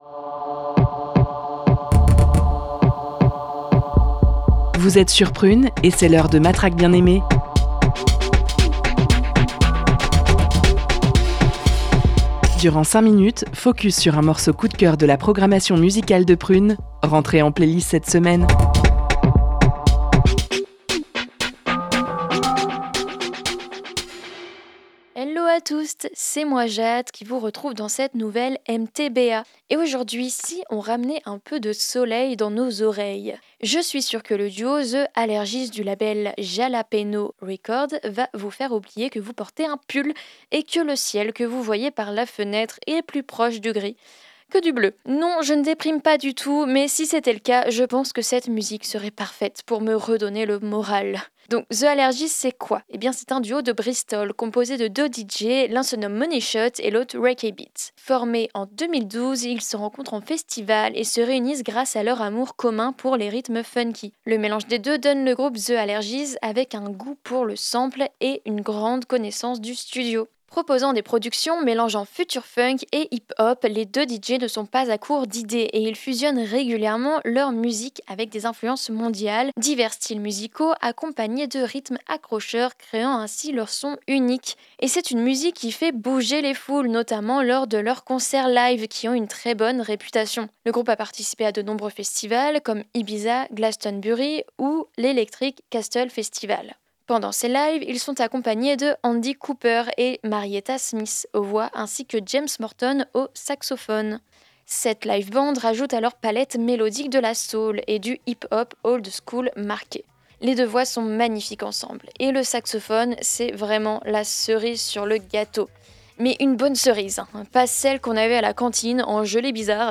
Des chansons feel good en quantité et en qualité !